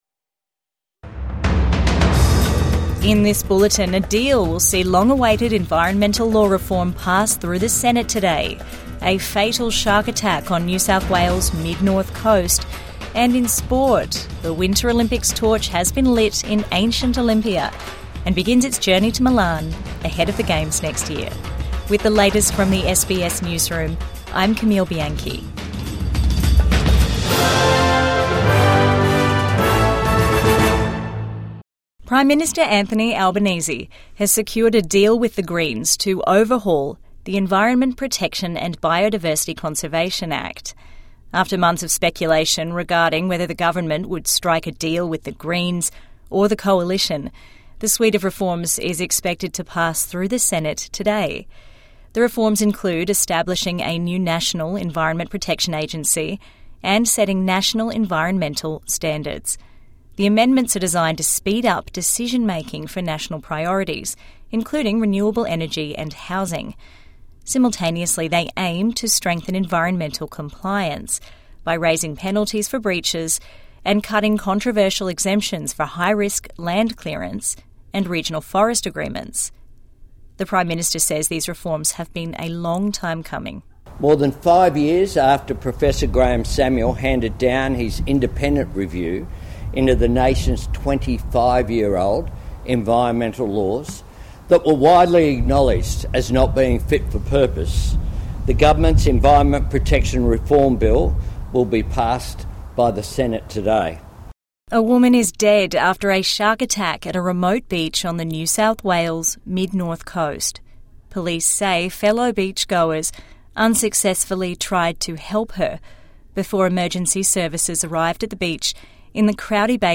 Late deal saves environmental law reforms | Midday News Bulletin 27 November 2025